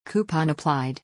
coupon_applied.mp3